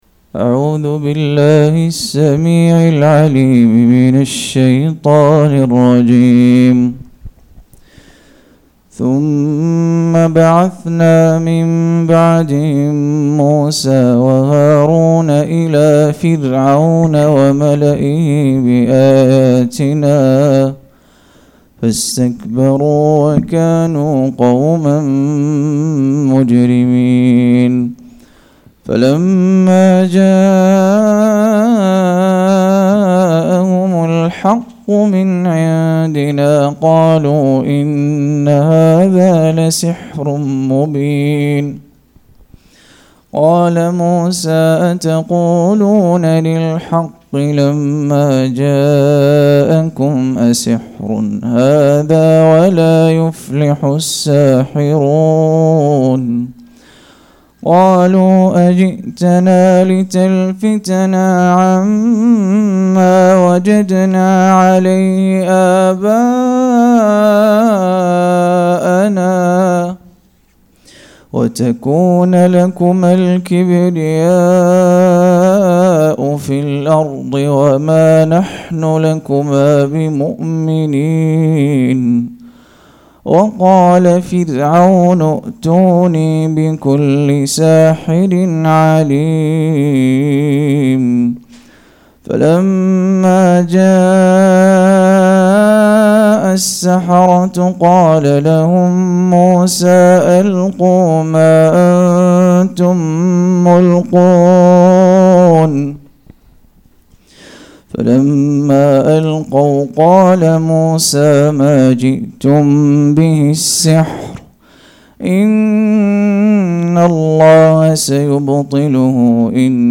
207- عمدة التفسير عن الحافظ ابن كثير رحمه الله للعلامة أحمد شاكر رحمه الله – قراءة وتعليق –